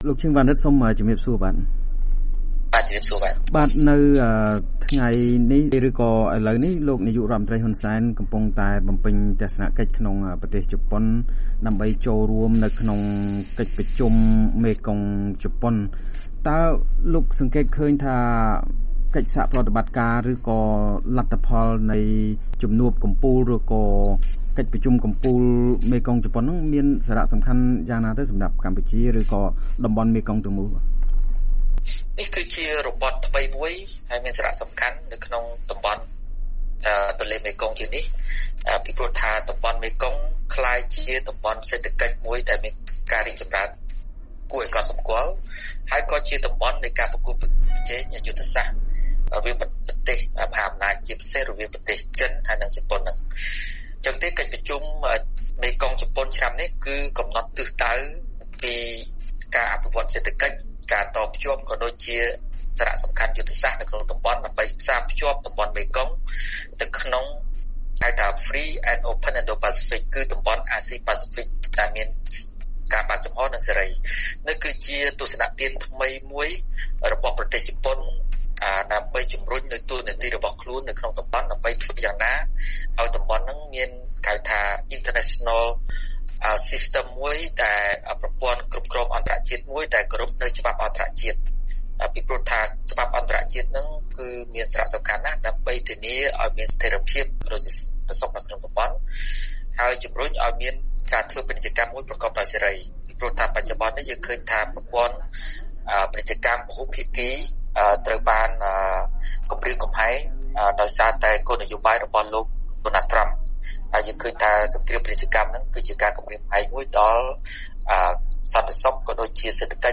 បទសម្ភាសន៍ VOA៖ អ្នកជំនាញកិច្ចការតំបន់ថា កិច្ចប្រជុំកំពូលមេគង្គ ជប៉ុន មានសារៈសំខាន់